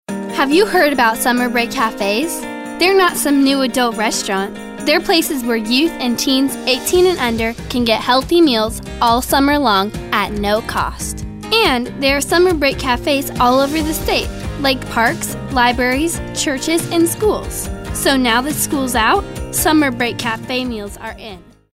animated, anti-announcer, confident, conversational, cool, friendly, genuine, girl-next-door, kid-next-door, real, sincere, teenager, upbeat, young, younger